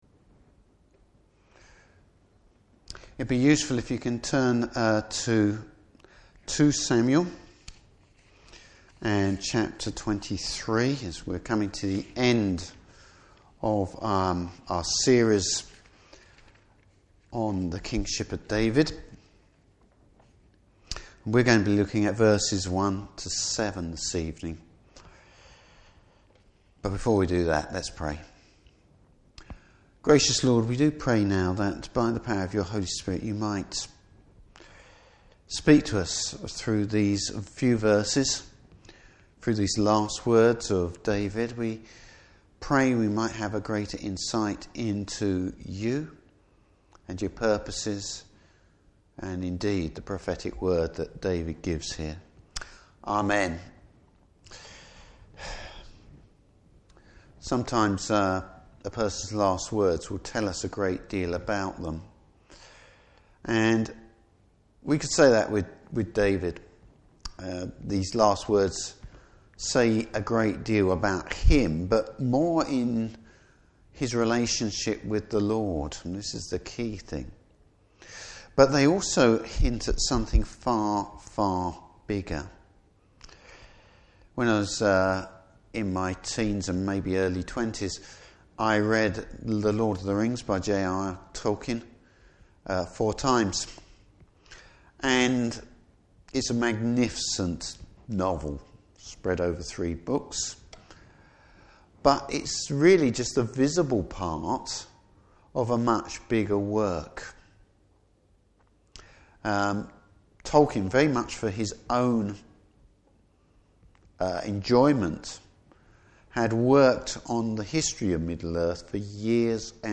Service Type: Evening Service David looks back and then looks to the future God has promised.